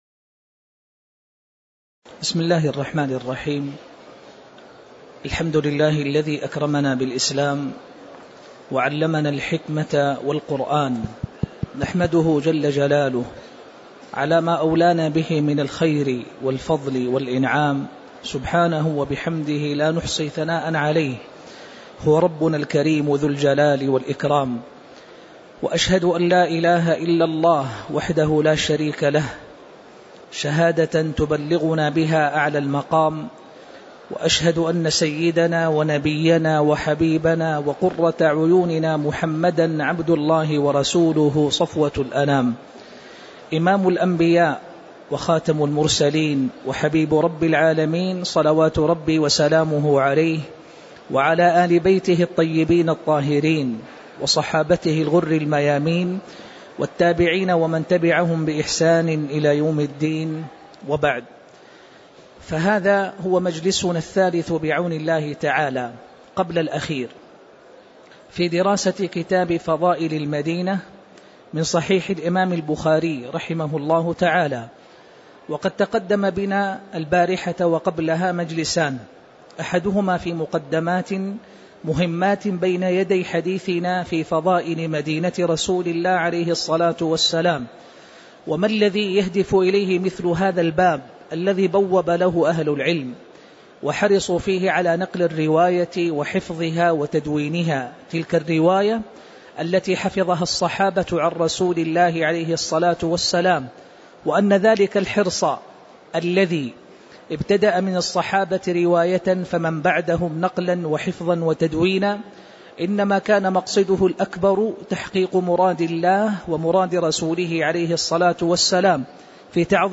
تاريخ النشر ٥ جمادى الآخرة ١٤٣٧ هـ المكان: المسجد النبوي الشيخ